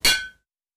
Bat Hit Aluminum Short.wav